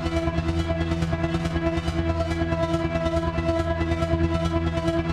Index of /musicradar/dystopian-drone-samples/Tempo Loops/140bpm
DD_TempoDroneB_140-E.wav